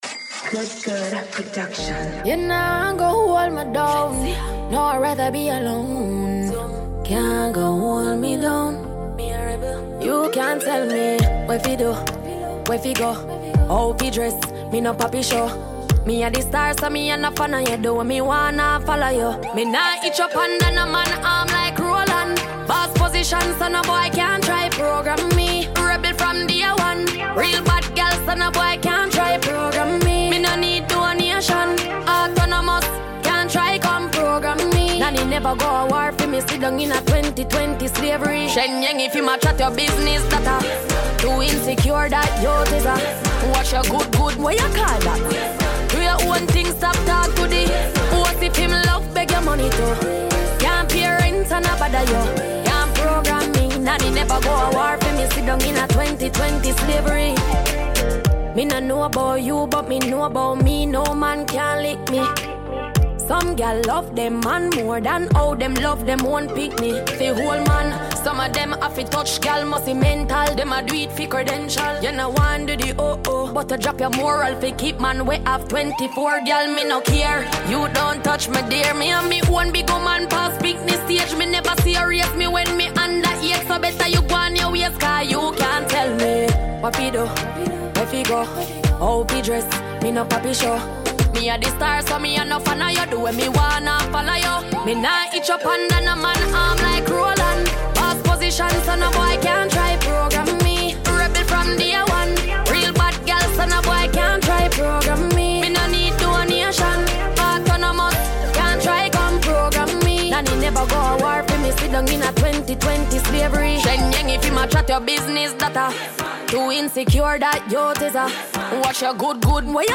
Dancehall/HiphopMusic